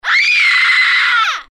Звуки страха, криков